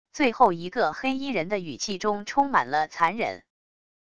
最后一个黑衣人的语气中充满了残忍wav音频生成系统WAV Audio Player